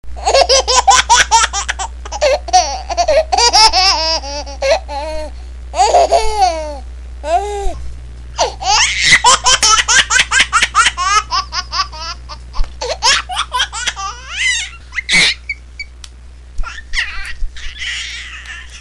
Звук - Смех ребенка
Отличного качества, без посторонних шумов.
18_smeh-rebenka.mp3